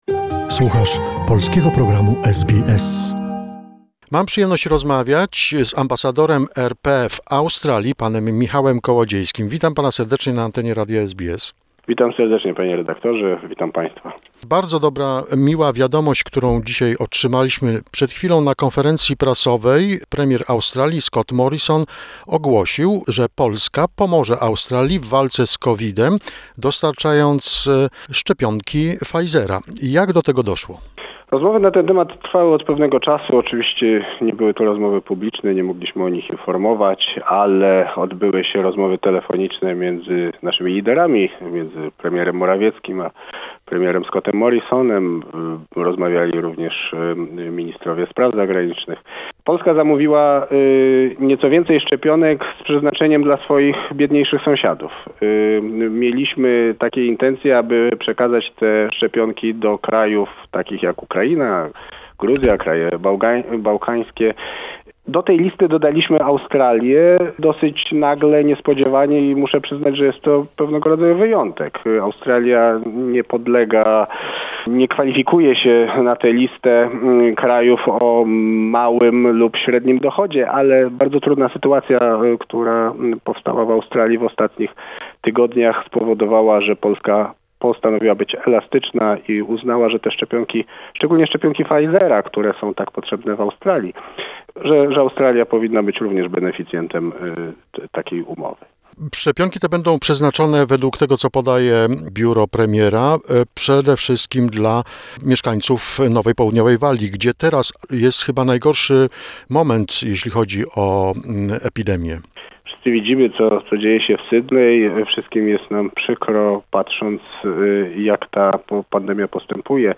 Polish Ambassador to Australia, Michał Kołodziejski, talks about how Poland will help Australia in the fight against Covid-19 by sending additional doses of the Pfizer vaccine to Australia ... The information was announced today at a press conference by Australian Prime Minister Scott Morrison.